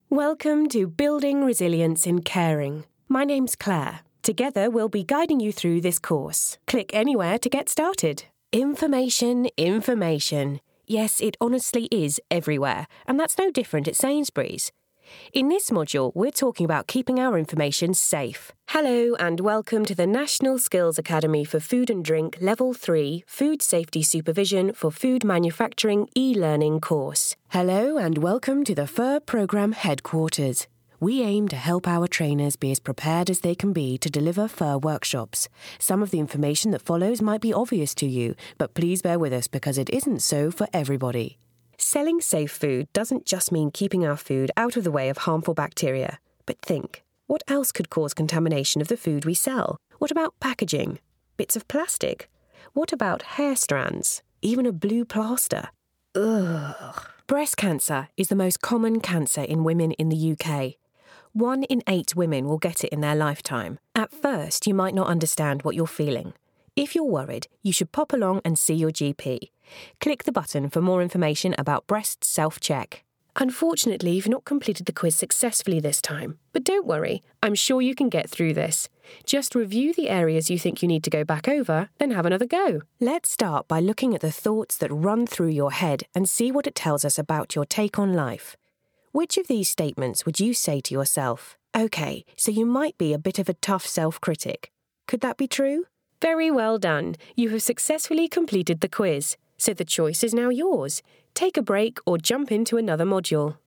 English (British)
E-learning
My warm, authentic and yet professional tone is a popular choice with clients. I'm versatile, easy to work with and my broadcast quality studio wont let you down.
Mezzo-Soprano